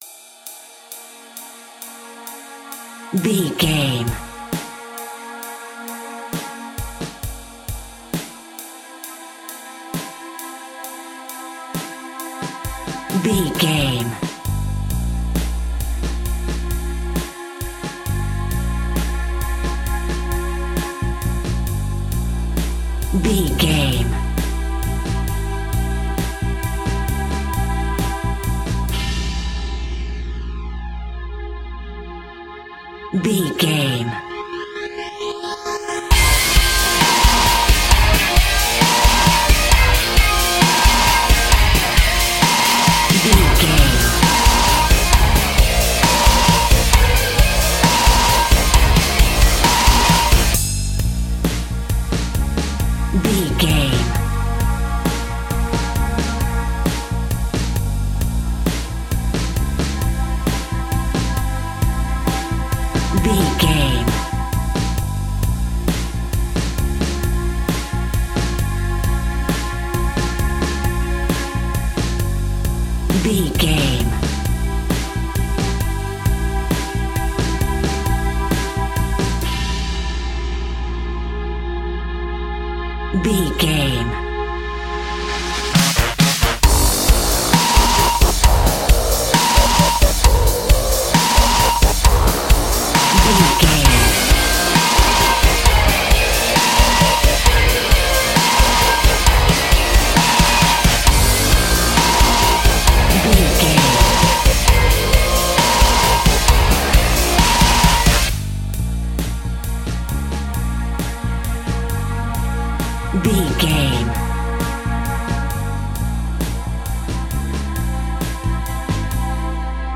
Aeolian/Minor
B♭
synthesiser
electric guitar
drums